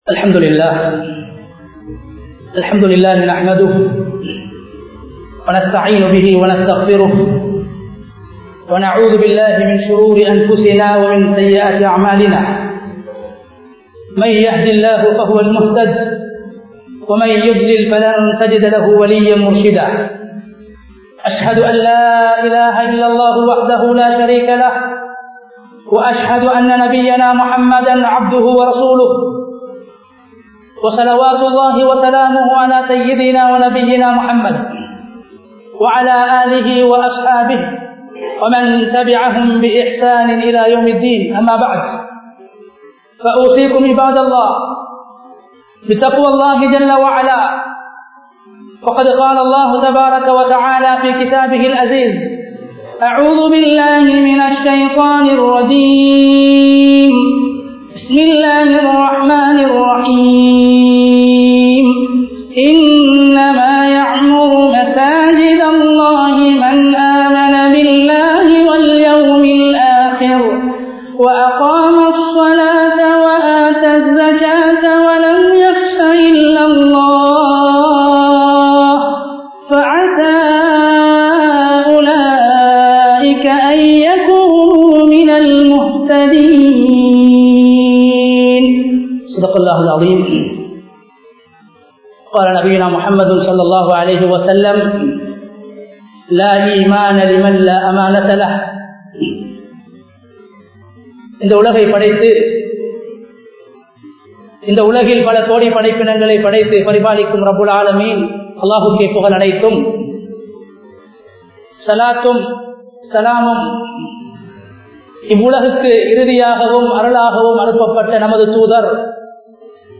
மஸ்ஜித் நிருவாகத்திற்கு தகுதியானவர்கள் யார்? (Who is Eligible for Masjid Administration?) | Audio Bayans | All Ceylon Muslim Youth Community | Addalaichenai